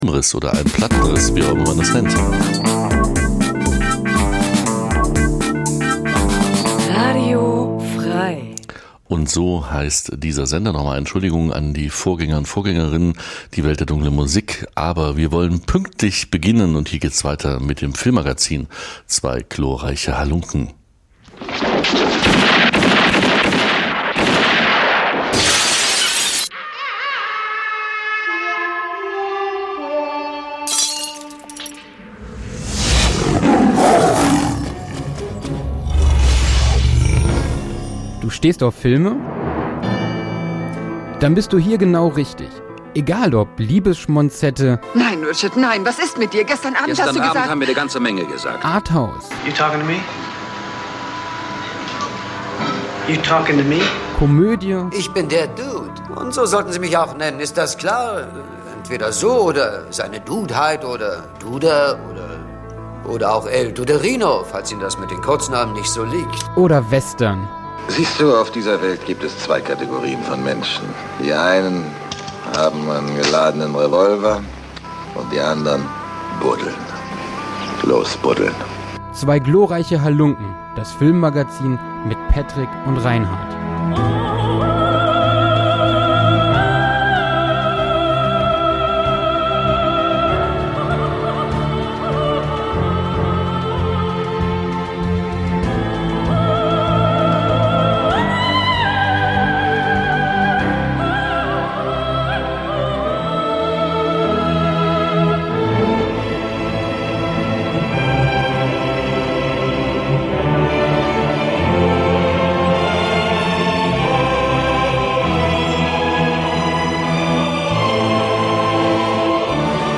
Egal ob Klassiker, Neuerscheinung oder Kultfilm, es wird f�r jeden Filminteressierten etwas vorgestellt und besprochen. Untermalt wird das Filmmagazin mit einer gro�en Auswahl an bekannten und unbekannten Musiktiteln, welche sorgsam aus Filmem ausgesucht sind.
Das andere Filmmagazin Dein Browser kann kein HTML5-Audio.